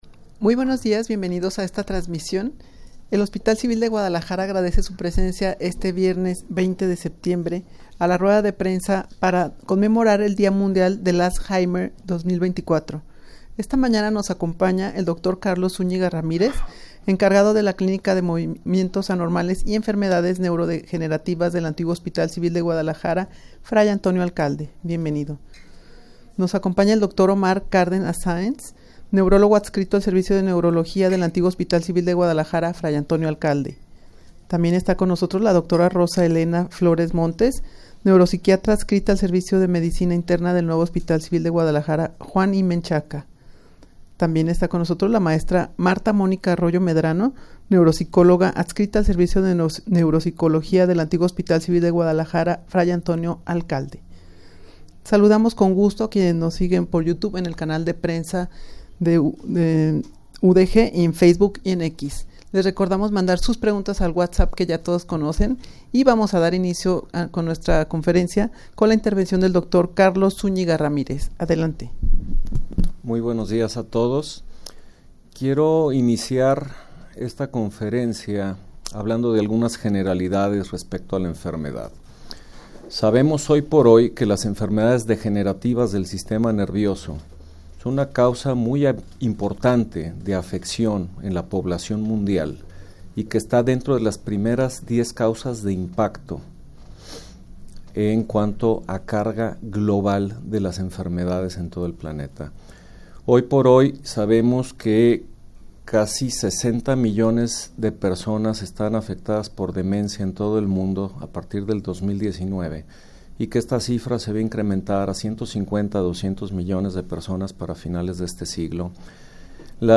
Audio de la Rueda de Prensa
rueda-de-prensa-para-conmemorar-el-dia-mundial-del-alzheimer-2024.mp3